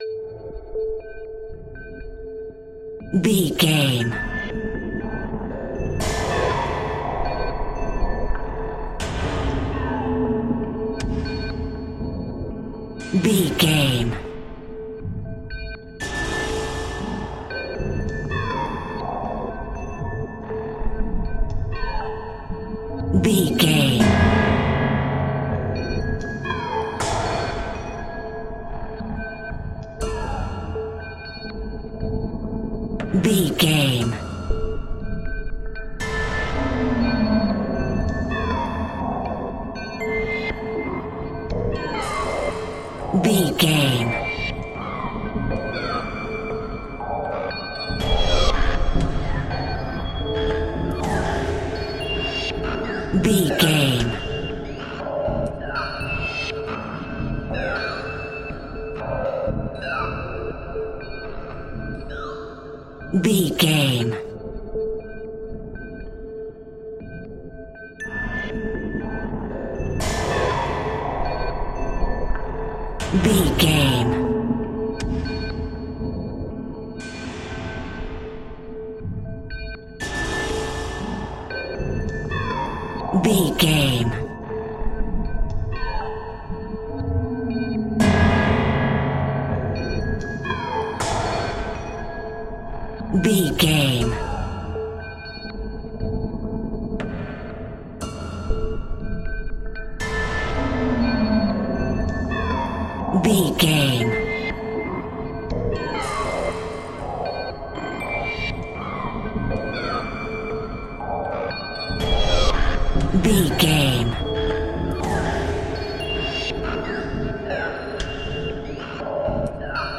Aeolian/Minor
tension
ominous
dark
suspense
haunting
eerie
percussion
synthesiser
Horror Synths
atmospheres